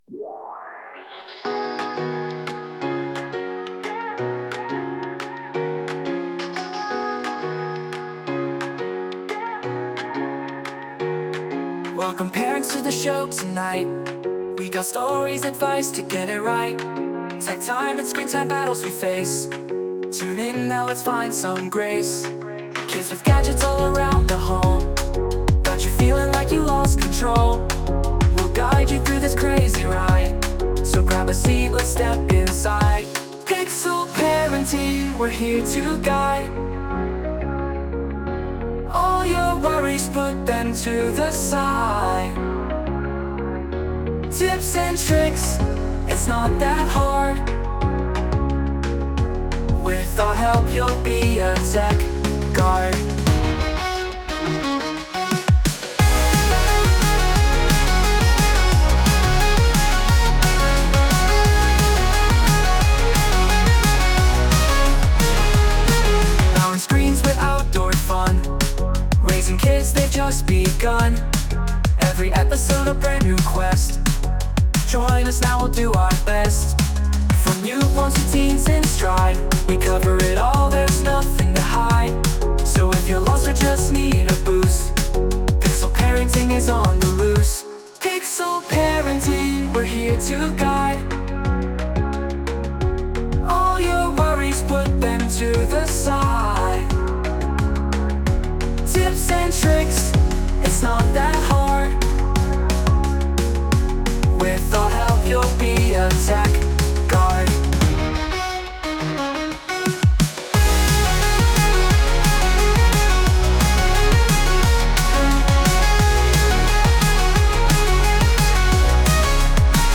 Here’s a fun little song made by AI to brighten your day. No deep parenting insights here—just some lighthearted, tech-inspired fun for your day!